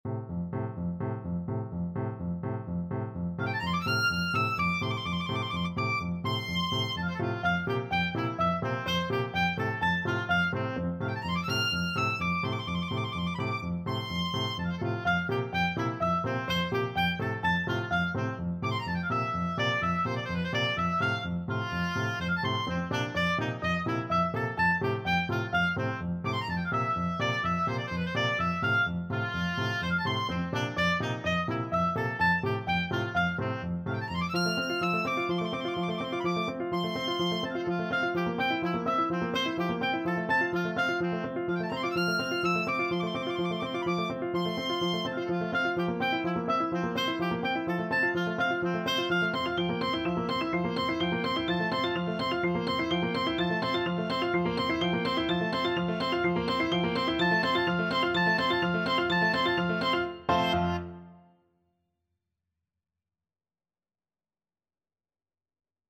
Oboe
F major (Sounding Pitch) (View more F major Music for Oboe )
Allegro moderato (=126) (View more music marked Allegro)
4/4 (View more 4/4 Music)
Classical (View more Classical Oboe Music)
chinese_dance_tchaikovsky_OB.mp3